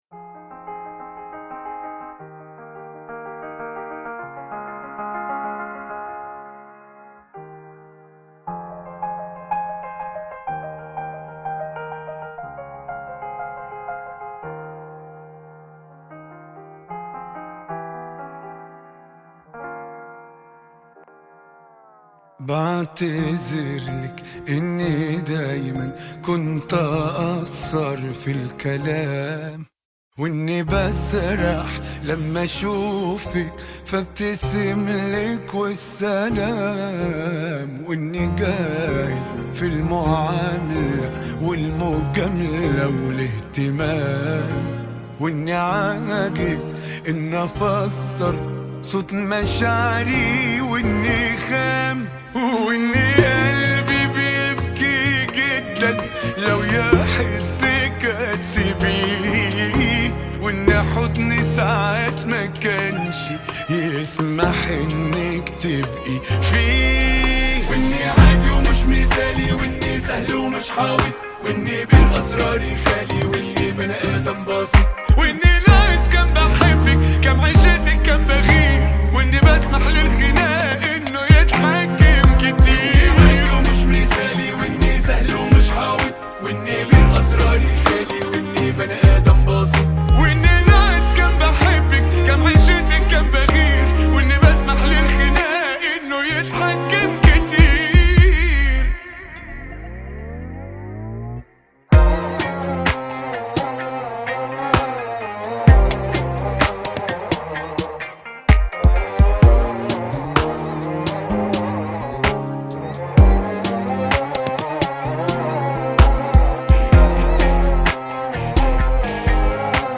Arab Song